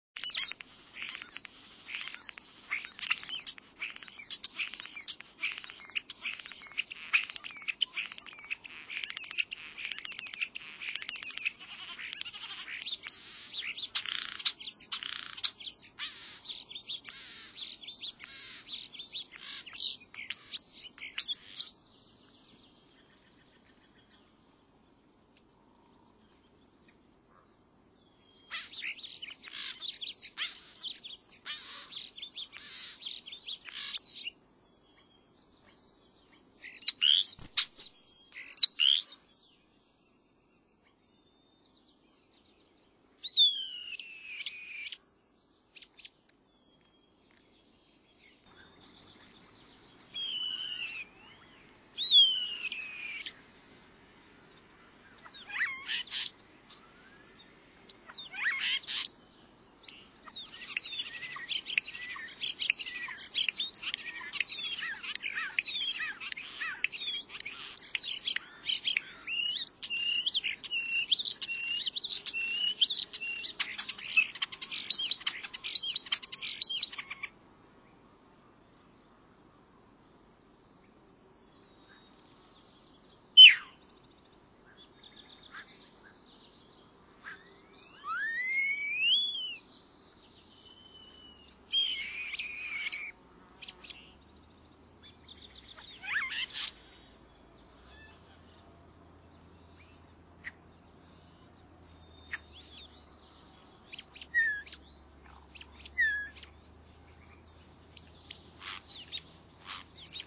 European Starling
Bird Sound
Song is a rather quiet series of rattles and whistled notes, often containing mimicry of other bird species. Flight call a purring "prurrp."
EuropeanStarling.mp3